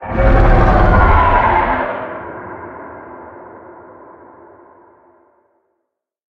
File:Sfx creature hiddencroc callout 04.ogg - Subnautica Wiki
Sfx_creature_hiddencroc_callout_04.ogg